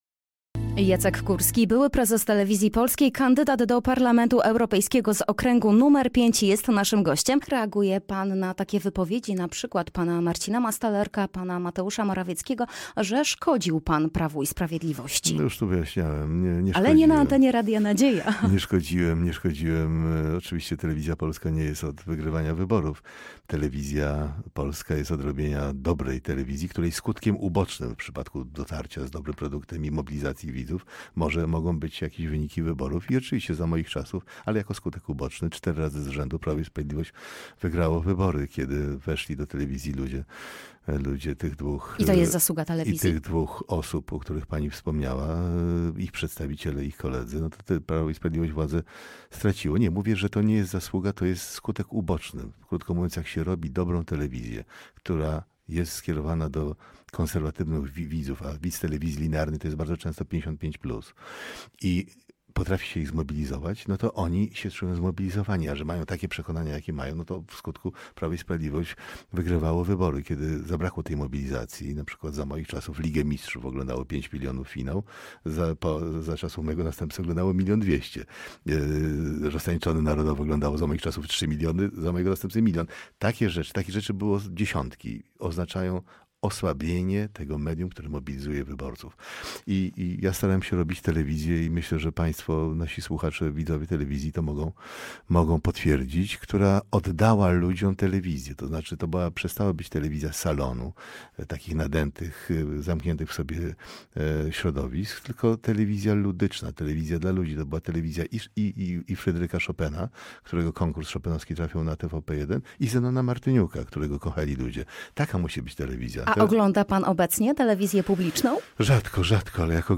Gościem Dnia Radia Nadzieja na ostatniej prostej do Europarlamentu był były prezes TVP – Jacek Kurski.